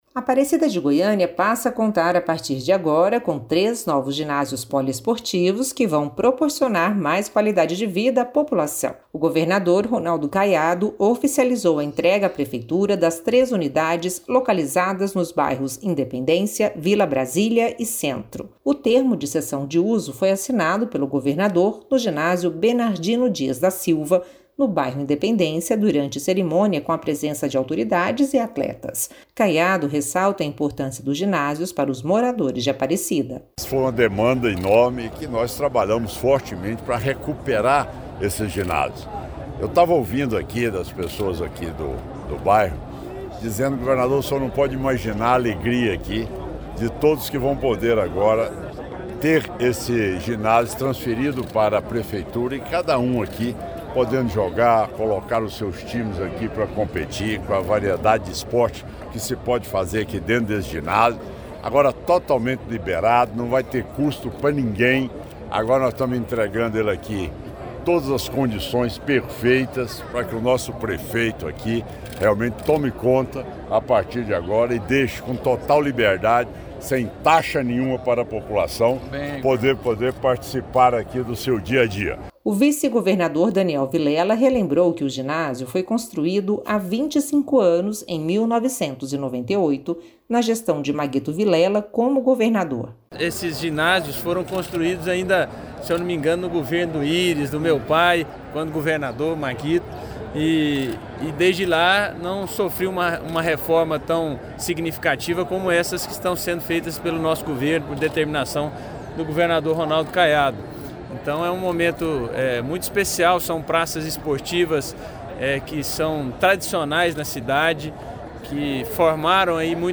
Rádio